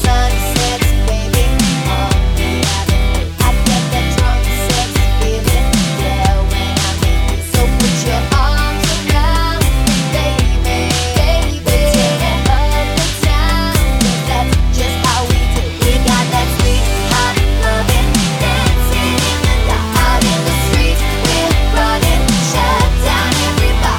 Explicit Pop (2010s) 4:03 Buy £1.50